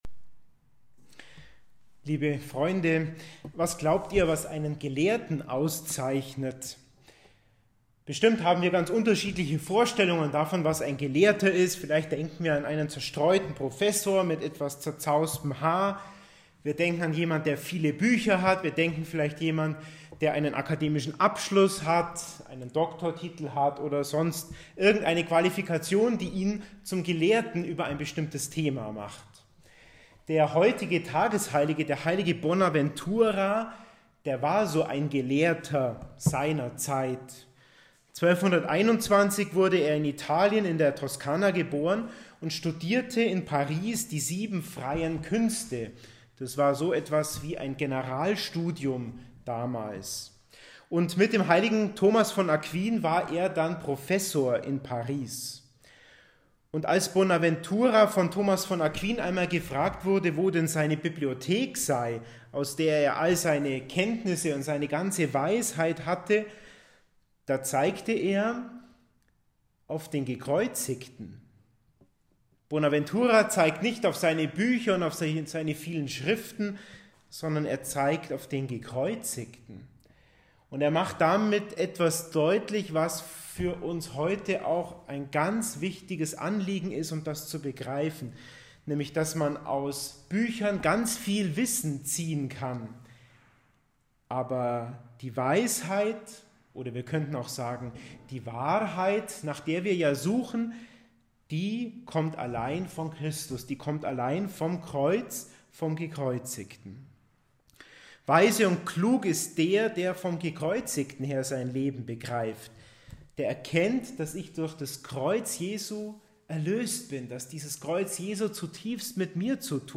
Predigt-Podcast